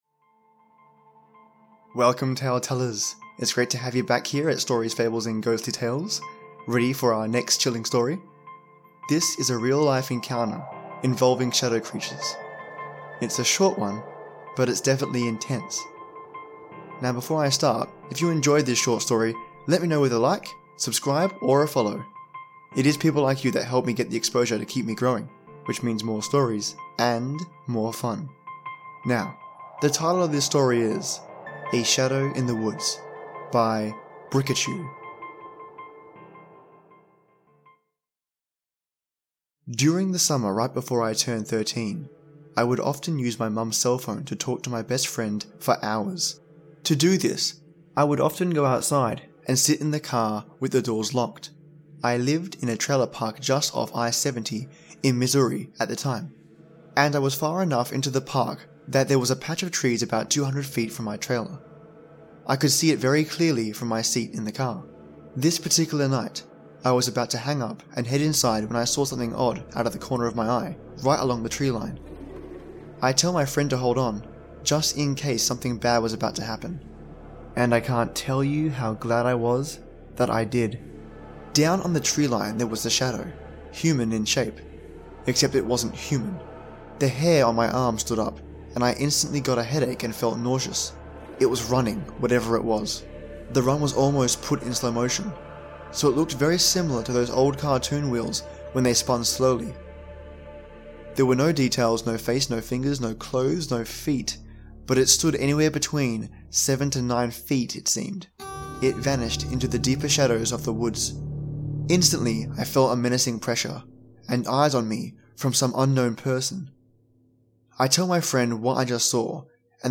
Dark Ambient Music